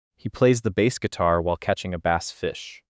English CMU 발음 교정은 같은 “bass” 단어에 대해 CMU 기호 주석 여부로 발음이 바뀌는지 확인용이었는데, 실제로 두 샘플의 발음이 다르게 나왔어요.
nonverbal/05_english_cmu_bass — CMU 주석 유/무에 따른 bass 발음 차이
nonverbal_05_english_cmu_bass.wav